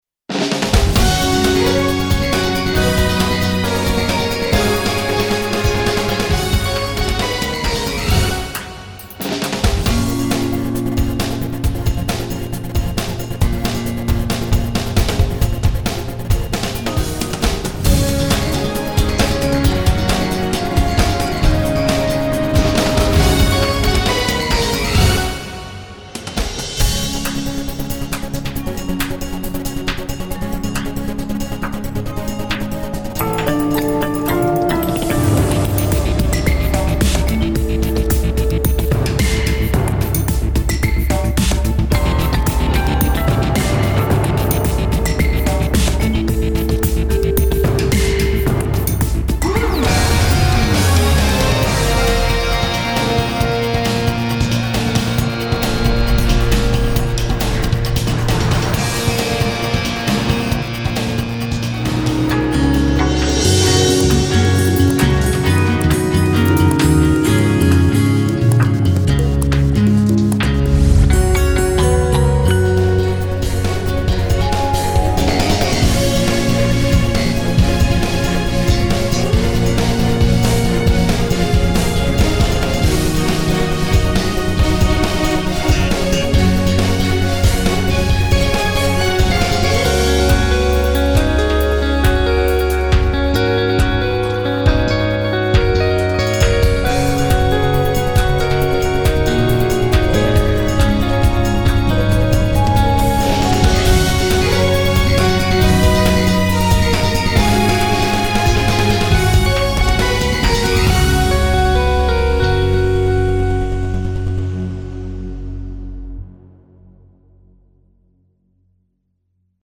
a fluid balance of acoustic and orchestral instrumentation